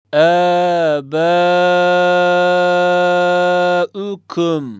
Üzerine konulduğu harfi dört elif miktarı uzatarak okutur